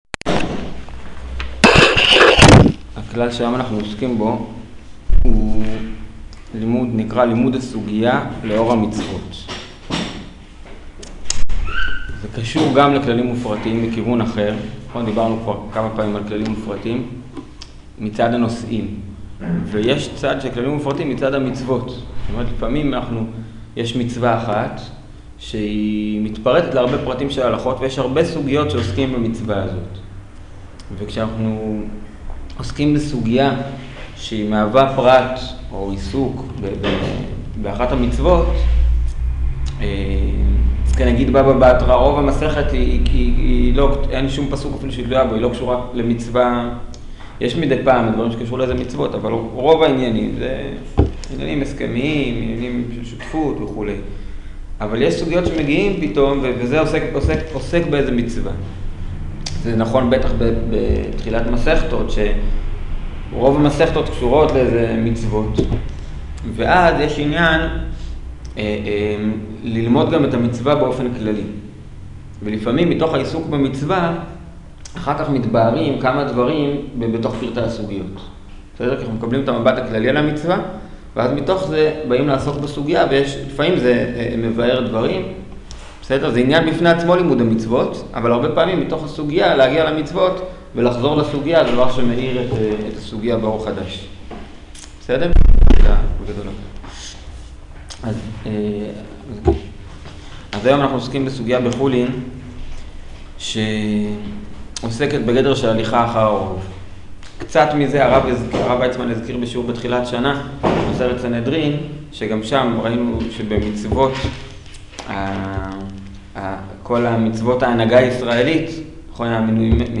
שיעור הליכה אחר הרוב